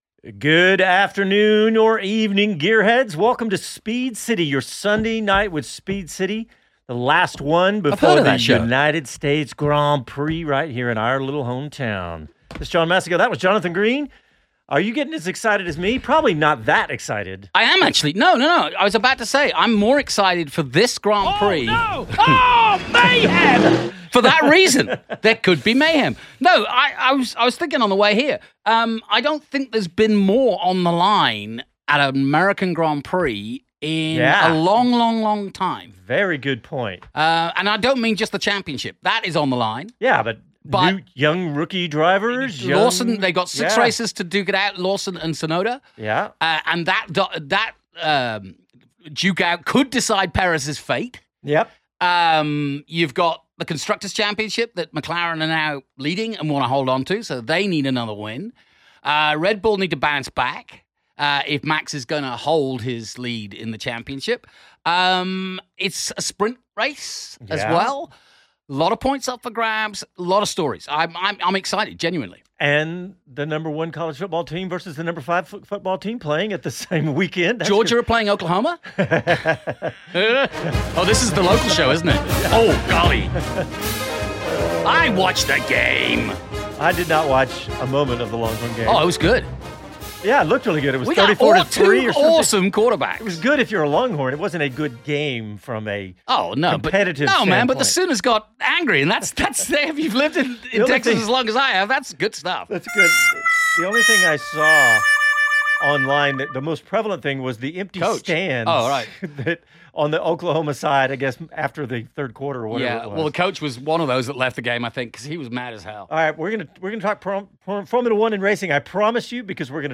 Your LIVE Sunday night motorsports show.
And TOYOTA is back in F1 through a technical partnership with Haas F1. Hear the radio station studio stream LIVE at 5:00 PM CT on Your Sunday Night with Speed City.